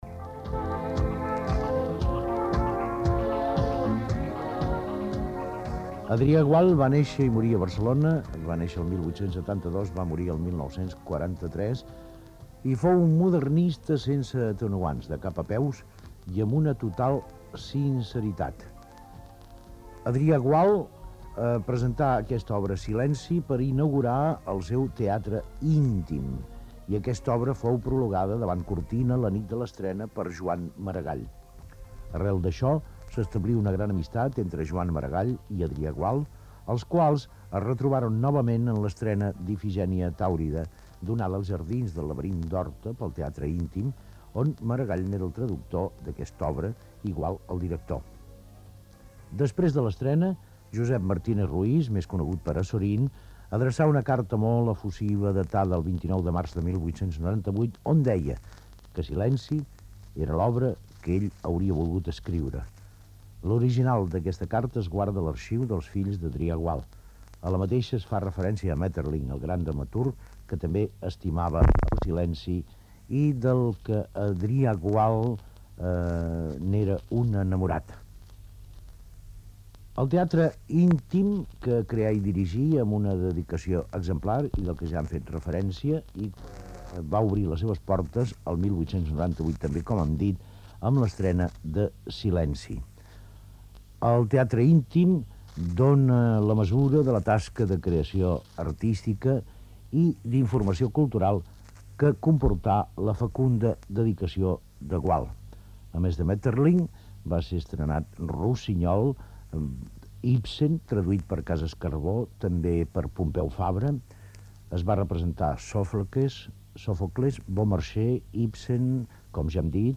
Perfil biogràfic d'Adrià Gual, repartiment, versió radiofònica de la seva obra "Silenci" Gènere radiofònic Ficció